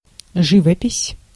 Ääntäminen
France: IPA: [la pɛ̃.tyʁ]